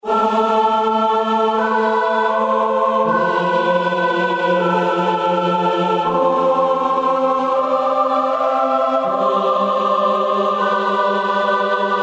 PS合唱团套装混合合唱团救世主
Tag: 80 bpm Cinematic Loops Vocal Loops 2.02 MB wav Key : Unknown